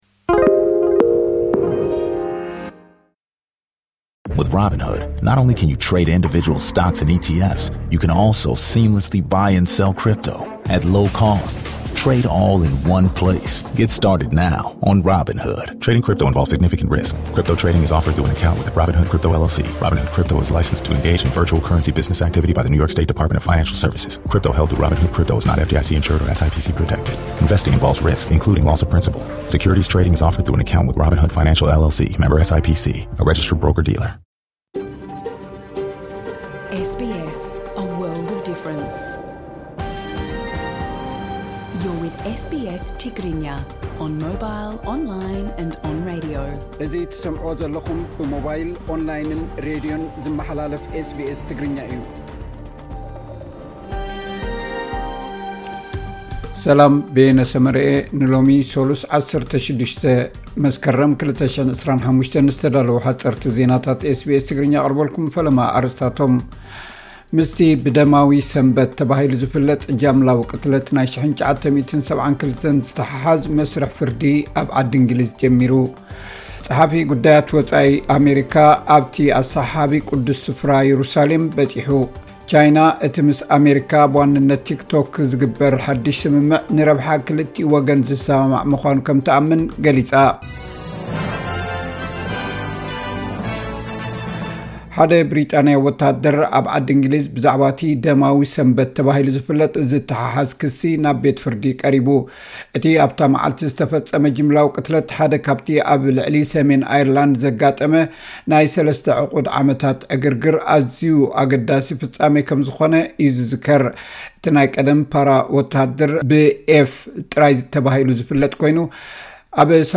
ሓጸርቲ ዜናታት ኤስ ቢ ኤስ ትግርኛ (16 መስከረም 2025)